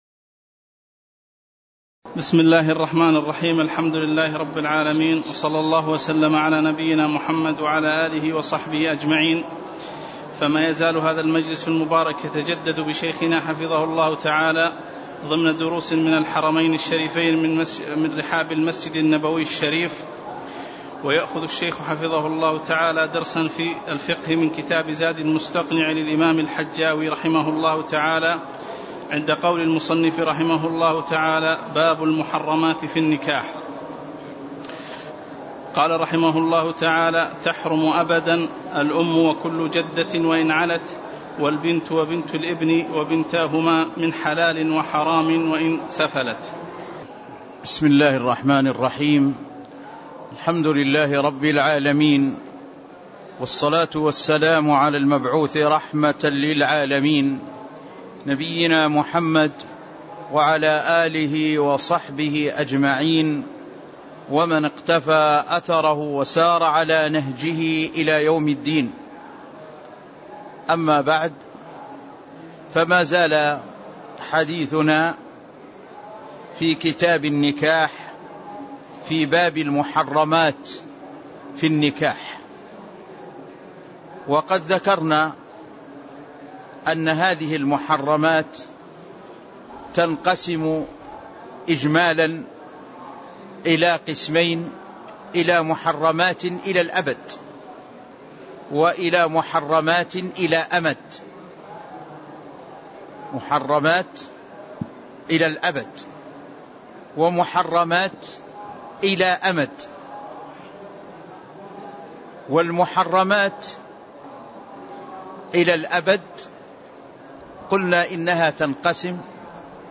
تاريخ النشر ٥ محرم ١٤٣٧ هـ المكان: المسجد النبوي الشيخ